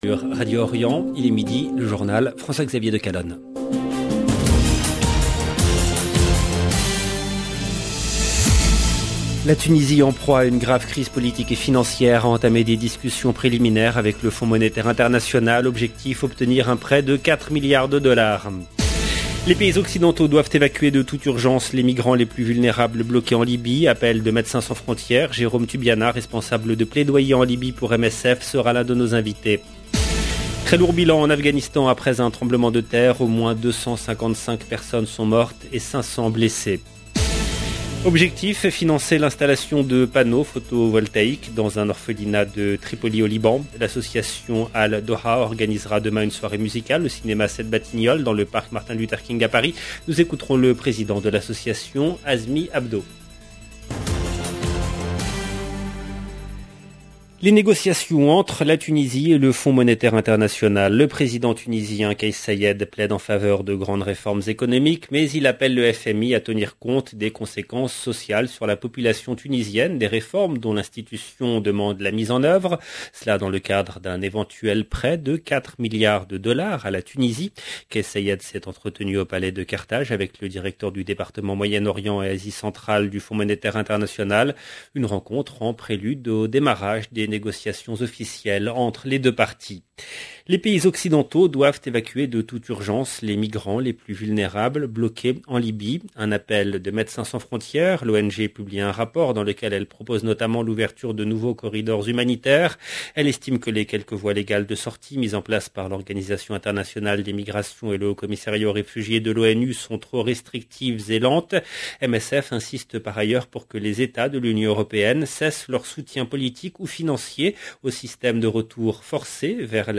LE JOURNAL EN LANGUE FRANCAISE DE MIDI DU 22/06/22